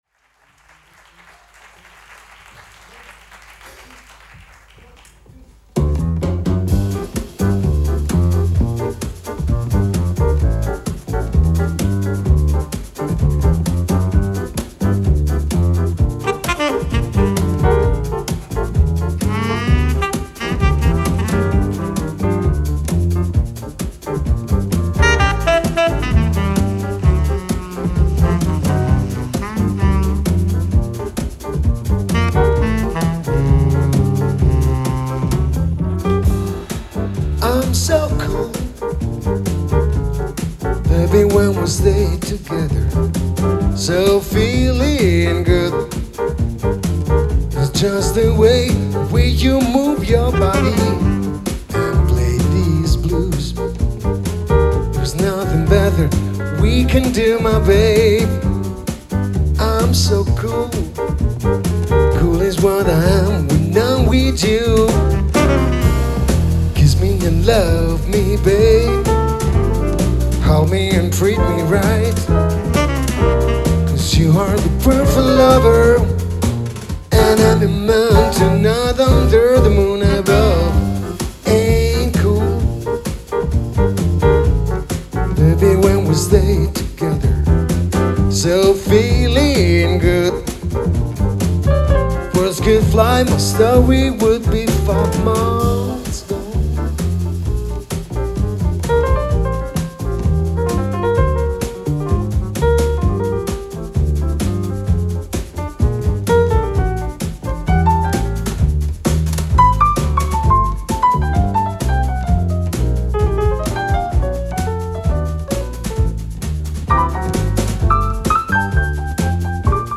vocal , percussions
pianoforte, keyboards
drums
tenor sax